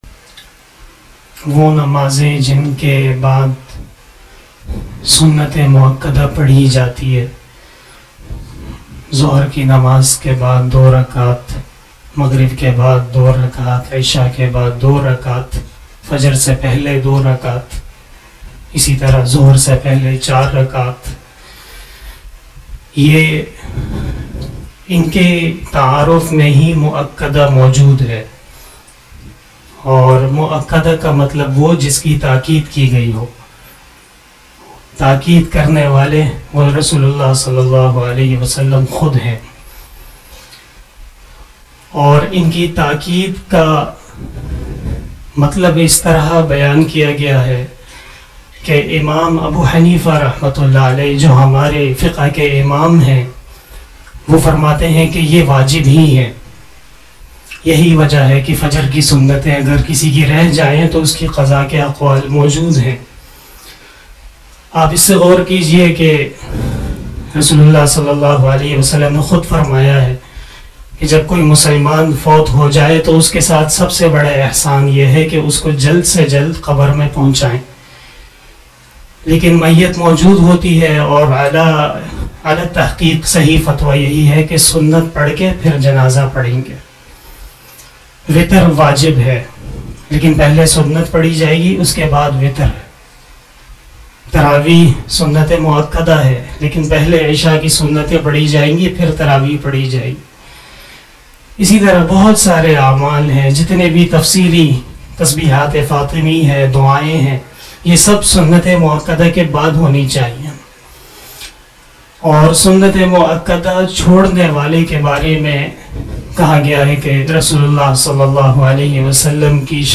After asar Namaz Bayan
بیان بعد نماز عصر